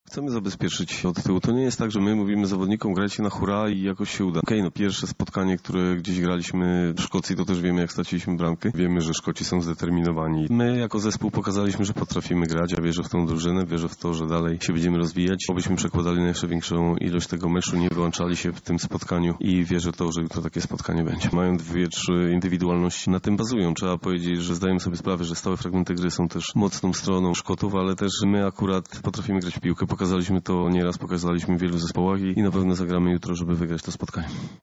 -mówi Michał Probierz, selekcjoner reprezentacji Polski.
Michal-Probierz-1.mp3